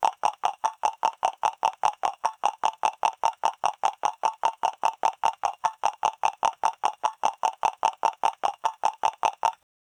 Big Bad Wolf, coming to tickle the prey with he´s long claws. 0:10 hooves clattering in the distance, getting louder 0:10 caballo galopando loop calidad para videojuego AAA el loop debe tener algo de variacion en los pasos, el sonido no debe ser agudo , mejor pasos con sonido mas grave 0:10 Horse galloping on a metal surface 0:15
caballo-galopando-loop-ca-7tn5cgxj.wav